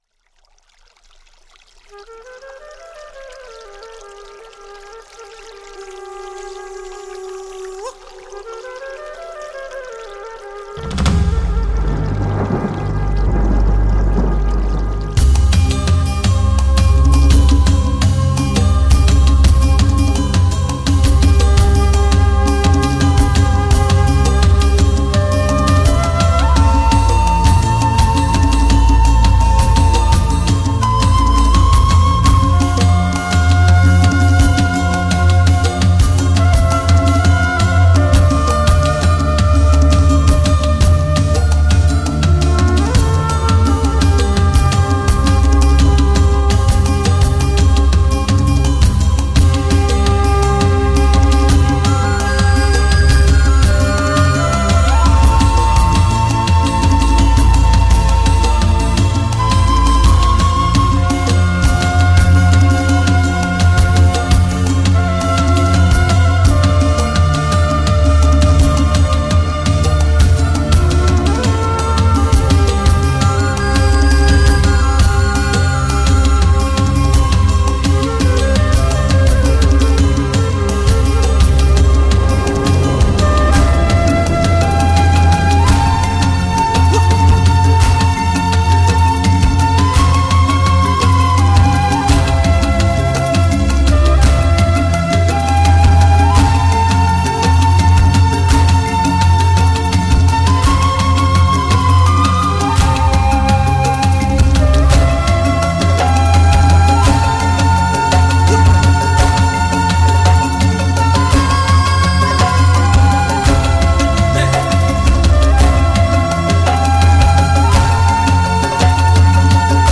前所未有音乐发烧奇迹，深度测试音响终极利器。
音、多层次、高定位的试音典范之作，试音终极者！